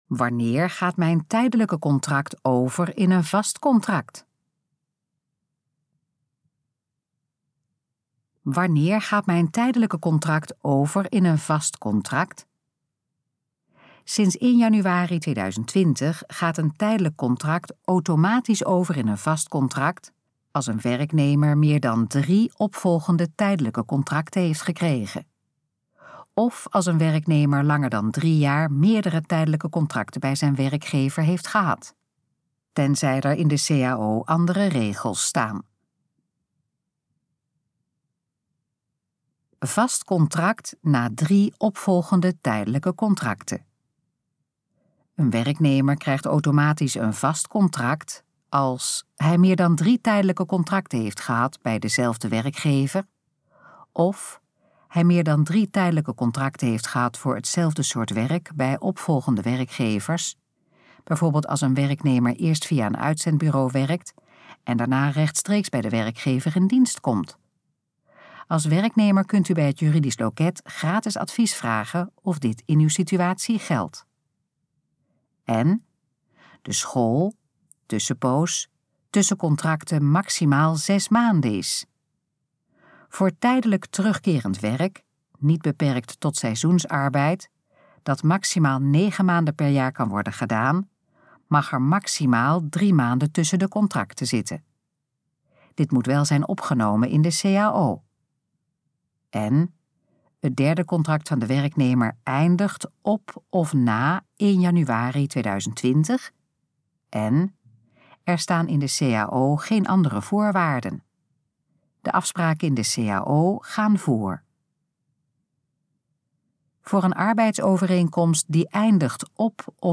Dit geluidsfragment is de gesproken versie van de pagina: Wanneer gaat mijn tijdelijke contract over in een vast contract?